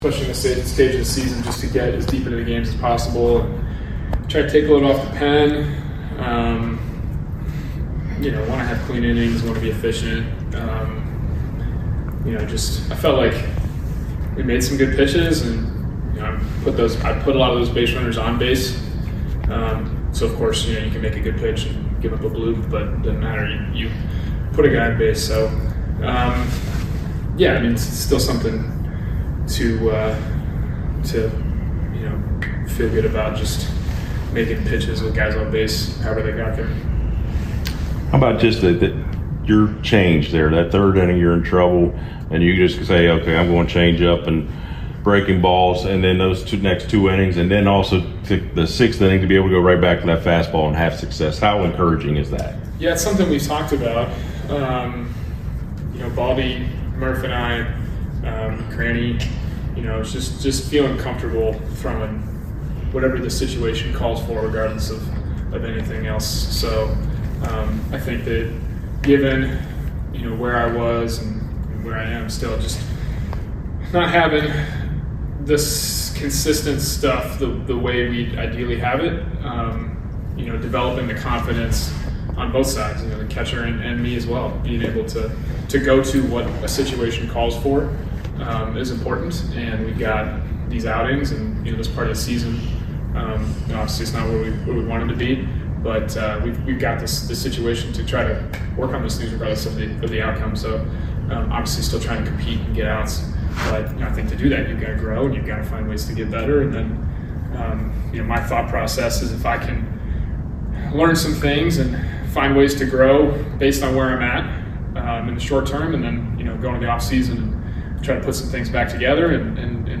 Atlanta Braves Pitcher Spencer Strider Postgame Interview after losing to the Chicago Cubs at Truist Park.